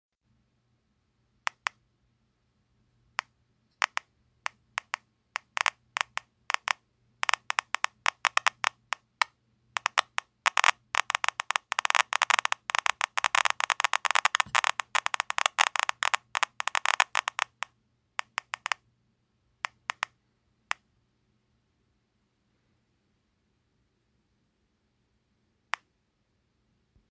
The clicks come from the speaker, and the key press beeps and alarms come from the beeper.
However it is done it gives a really nice old school proper click. It really sounds like a good vintage counter clicking away.
ygr01_clicks.mp3